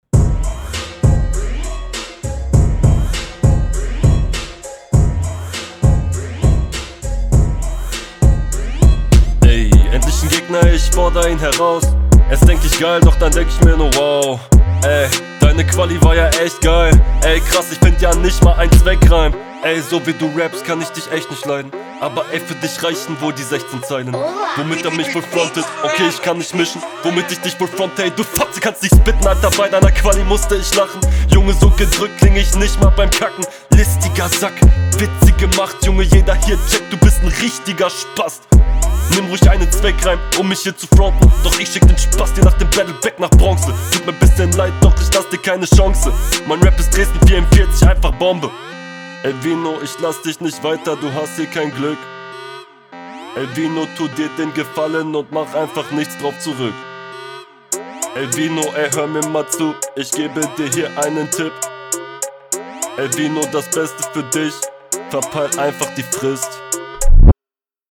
Flow ist gut - zumindest alles onpoint und es klingt routiniert.